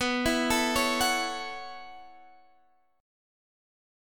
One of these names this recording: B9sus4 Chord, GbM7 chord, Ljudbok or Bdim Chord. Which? B9sus4 Chord